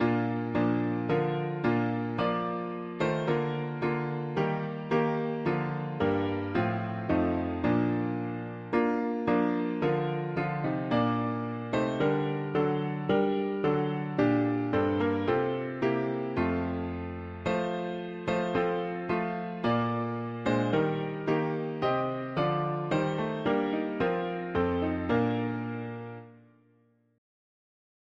As you sang creation’s story, now proclaim Messiah’s birth; come and worship,… english christian winter 4part chords
Key: B-flat major Meter: 87.87